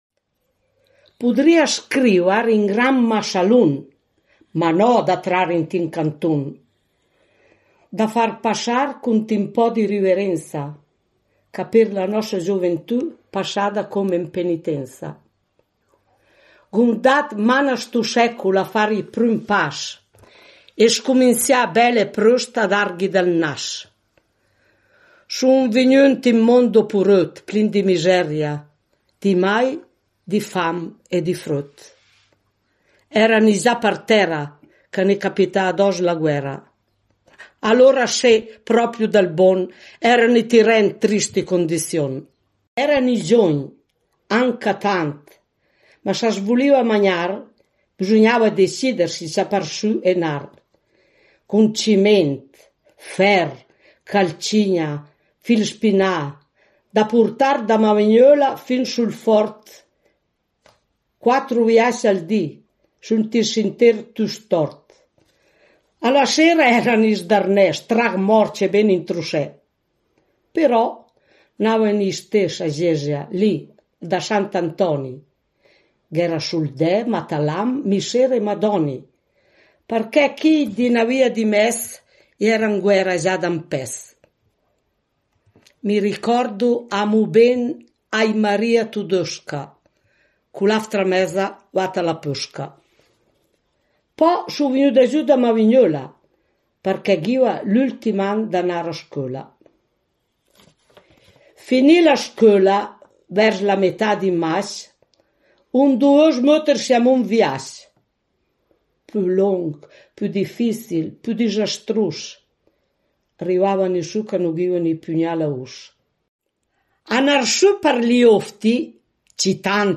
Ecco la poesia letta